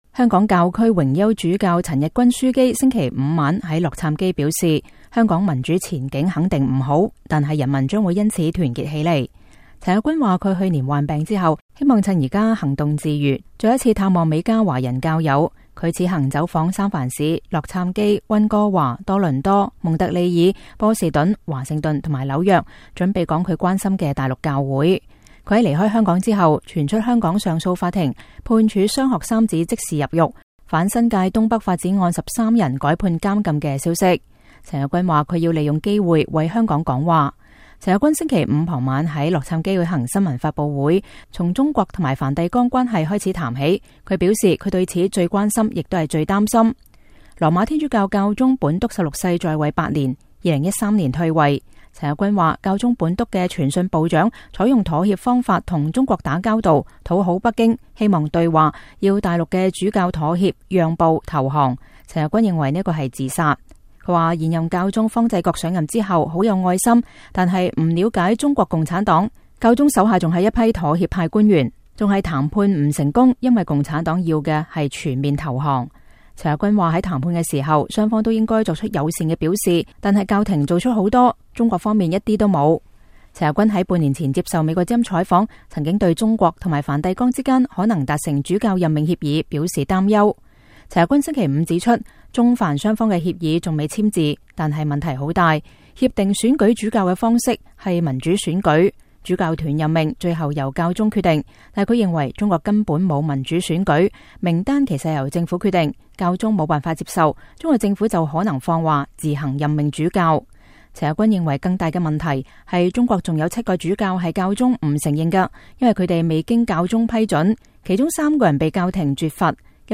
香港教區榮休主教陳日君樞機週五晚在洛杉磯表示，香港民主前景肯定不好，但人民將會因此團結起來。陳樞機星期五傍晚在洛杉磯舉行新聞發佈會，從中國和梵蒂岡關係開始談起，他表示他對此最關心也是最擔心。